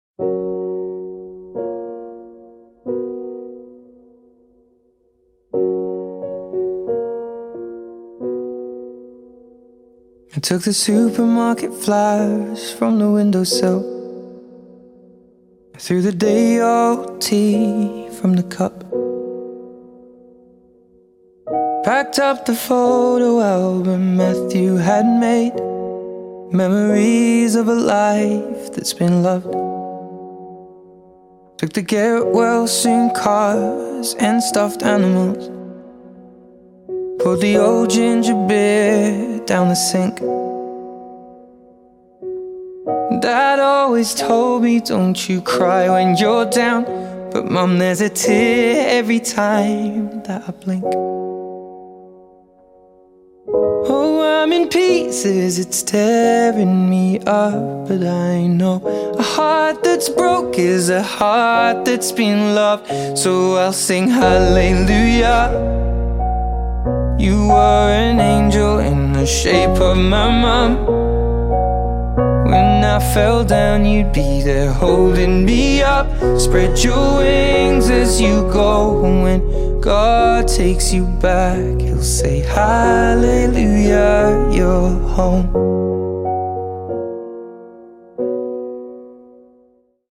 • Качество: 160, Stereo
мужской вокал
грустные
спокойные
пианино
романтичные
лиричные